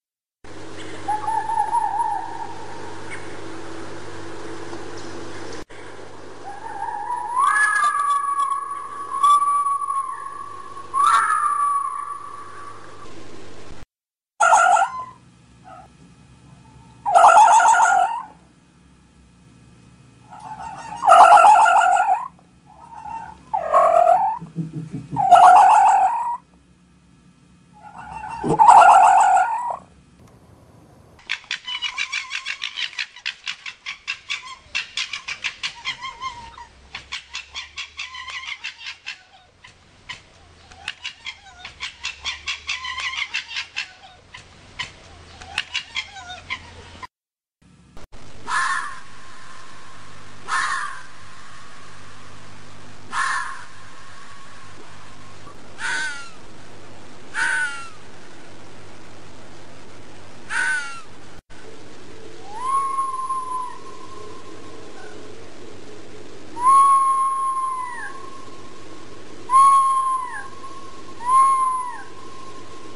Amusez-vous à reconnaître ces différents cris dans la petite compilation ci-dessous.
Fox_Calls.mp3